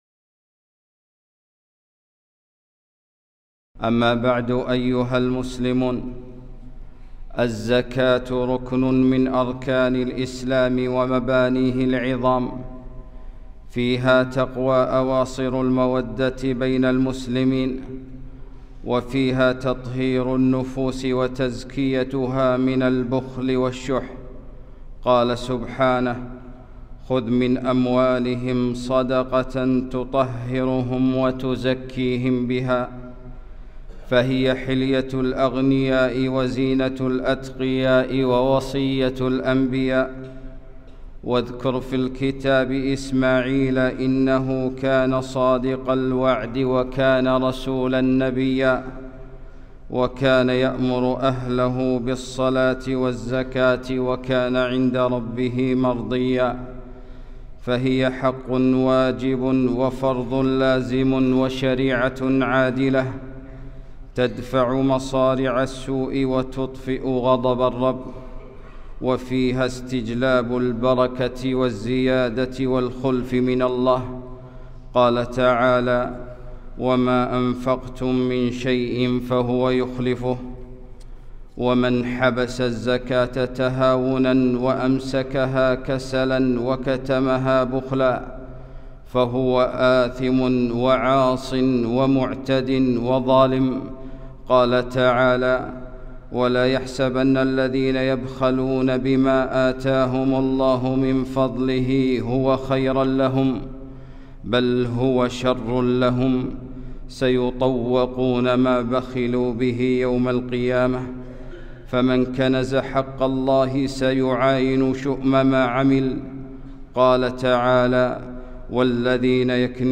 خطبة - الزكاة حق الله